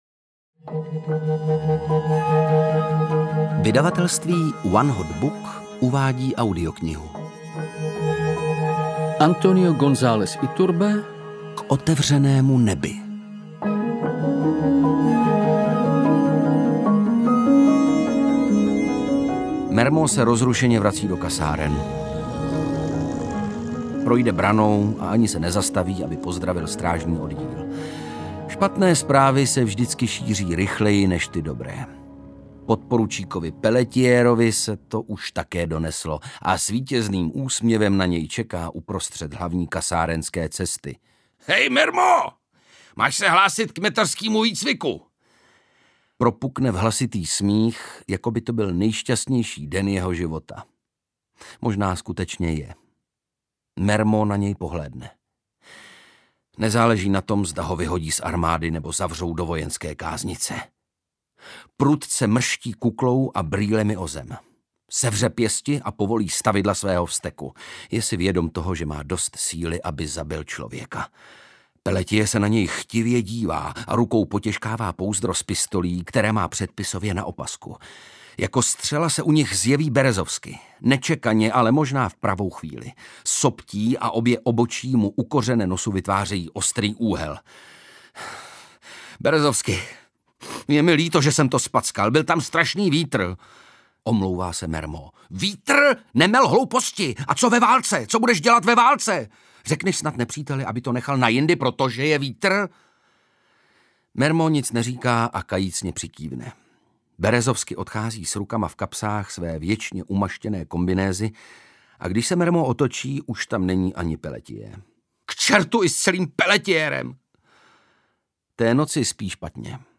K otevřenému nebi audiokniha
Ukázka z knihy
• InterpretVladimír Javorský, Ondřej Brousek